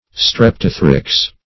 Search Result for " streptothrix" : The Collaborative International Dictionary of English v.0.48: Streptothrix \Strep"to*thrix\, n. [NL., fr. Gr.